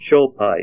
Name Pronunciation: Schoepite + Pronunciation Synonym: ICSD 82477 PDF 13-407 Schoepite Image Images: Schoepite Becquerelite Comments: Bright yellow crystals of schoepite on transparent yellow becquerelite.